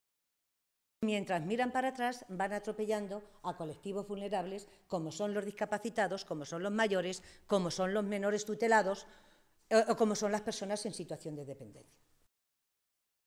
Matilde Valentín, portavoz de Asuntos Sociales del Grupo Socialista
Cortes de audio de la rueda de prensa